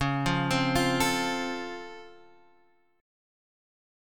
C#+M7 Chord
C#+M7 chord